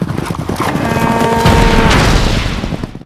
bullcharge.wav